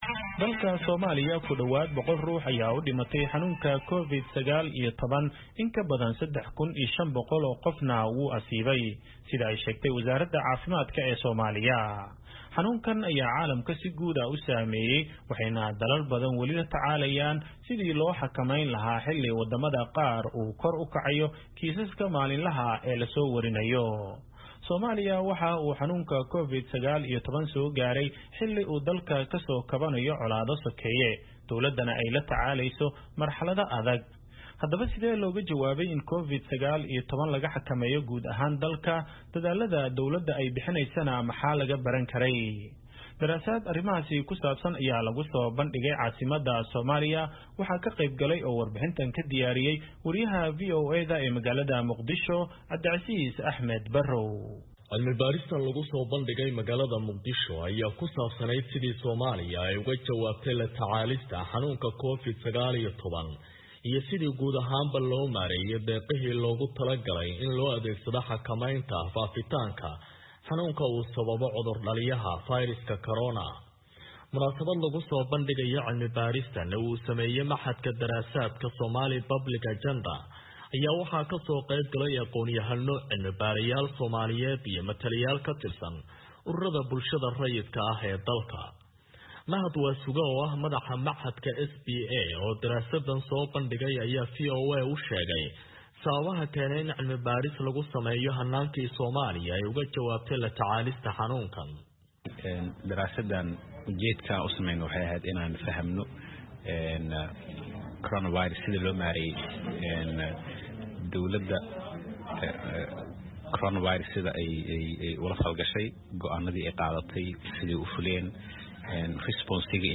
Daraasad arrimahaas ku saabsan ayaa lagusoo bandhigay caasimadda Soomaaliya.